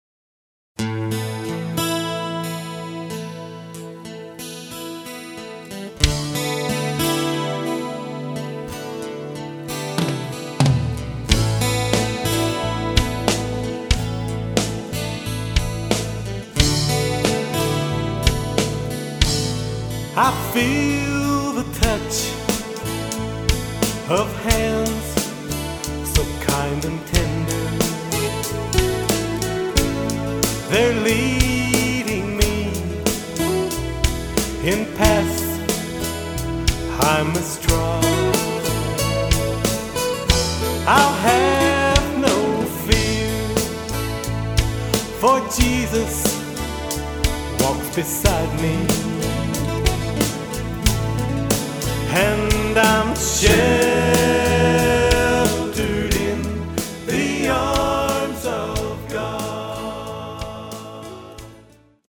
Autoharp, Lead & Harmony Vocals
Guitar
Fiddle